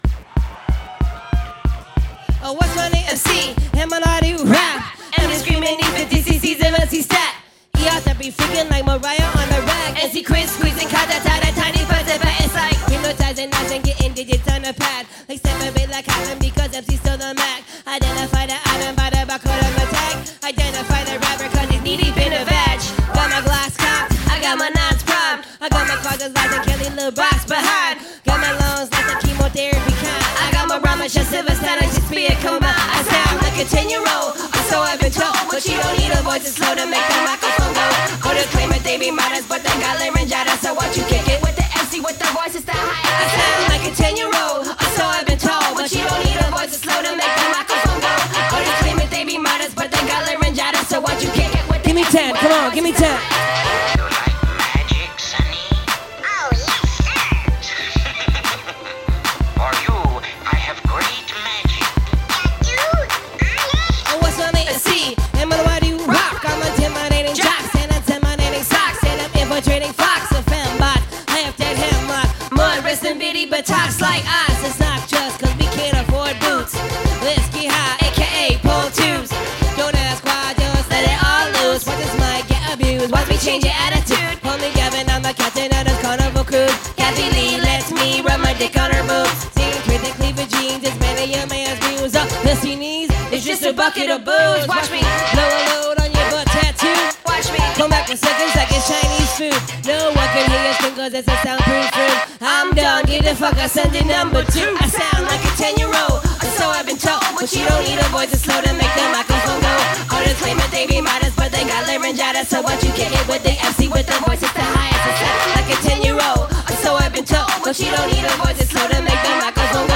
omaha, ne at the waiting room on october 15th 2009
live at the waiting room, soundboard recording